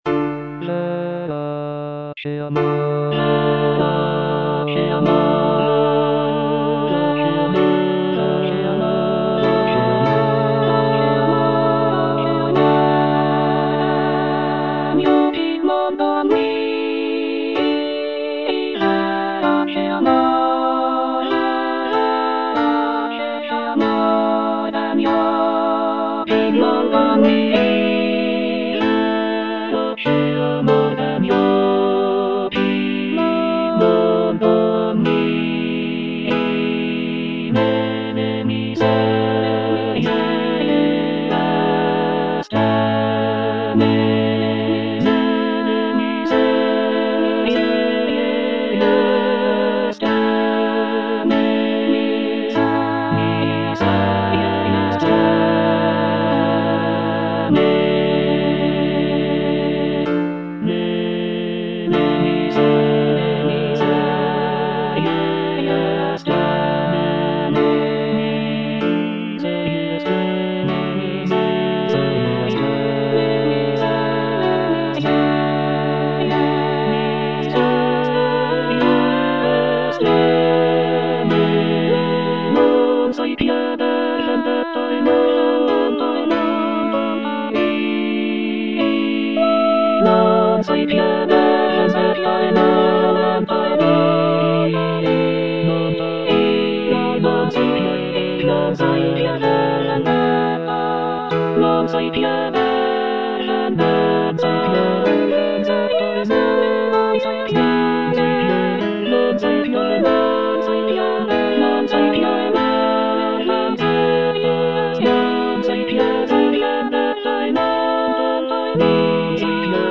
C. MONTEVERDI - LAMENTO D'ARIANNA (VERSION 2) Coro IV: Verace amor (All voices) Ads stop: auto-stop
It is a deeply emotional lament aria that showcases the singer's ability to convey intense feelings of grief and despair.
The music is characterized by its expressive melodies and poignant harmonies, making it a powerful and moving example of early Baroque vocal music.